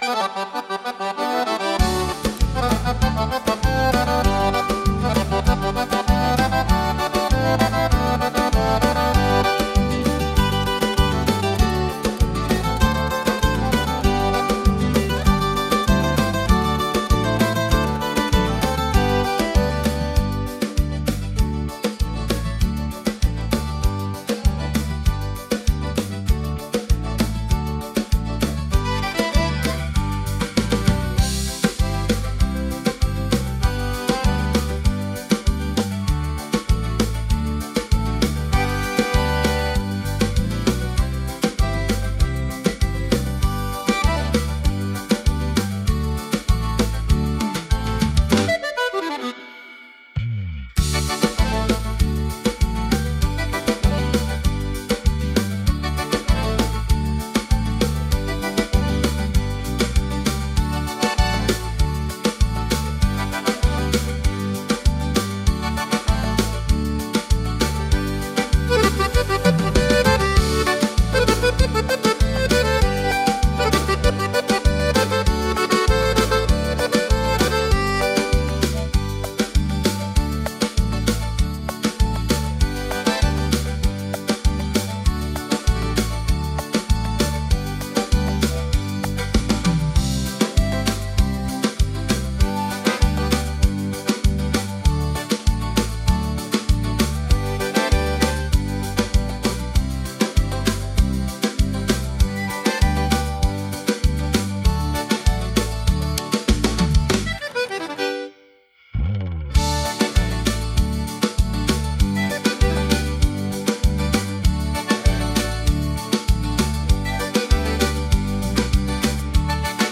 04-PLAYBACK_A Branca Flor do Cafezal - Bugio + Violão.wav